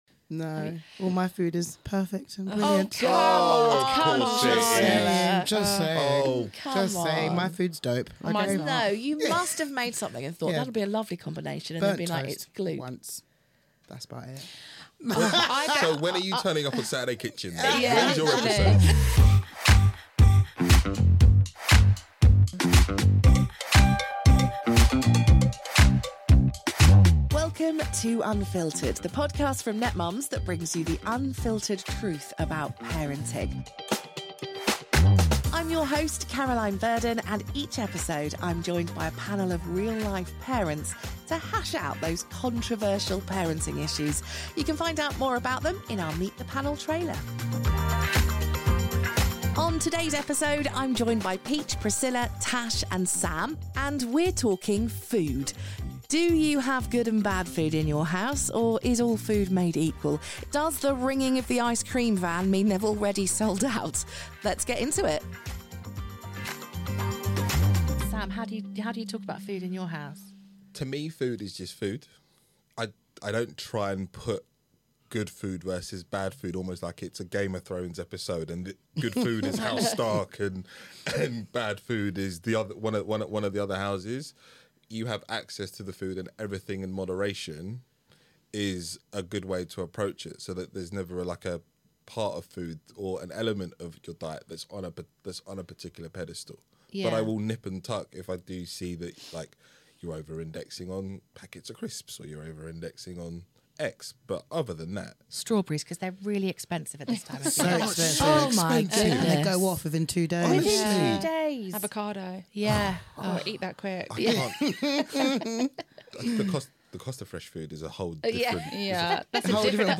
- Balancing nutrition with convenience in busy family lives - Cooking fails and funny food stories - Managing dietary preferences and allergies when visiting or hosting Join us for a lively discussion on the importance of balance, the dangers of food restrictions, and the humorous side of cooking mishaps.